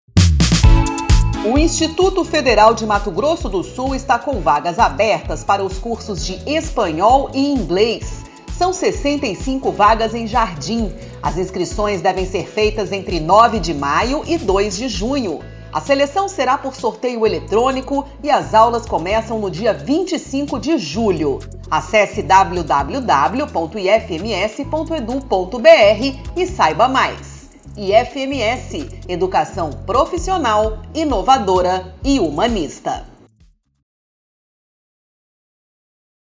Spot - Cursos de idiomas para o 2º semestre de 2022 em Jardim